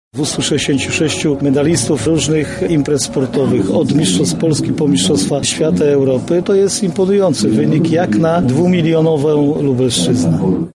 -mówi Jarosław Stawiarski, Marszałek Województwa Lubelskiego